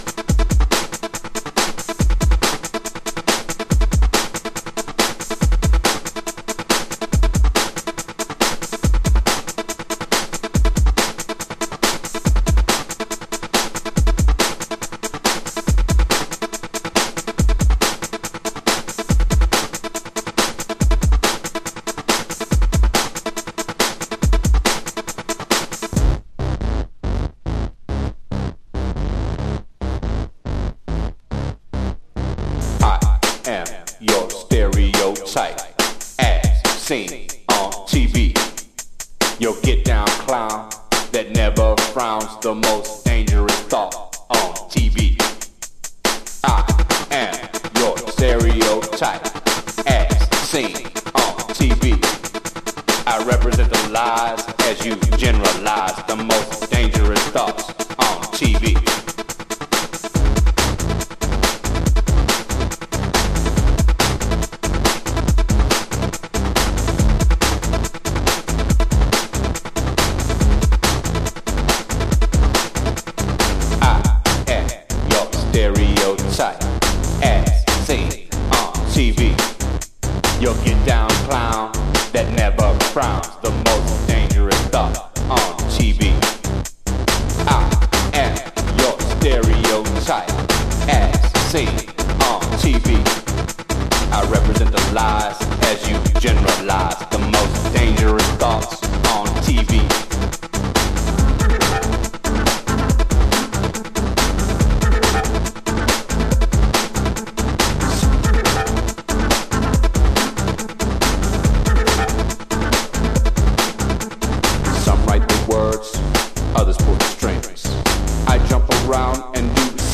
Detroit House / Techno
(Vocal)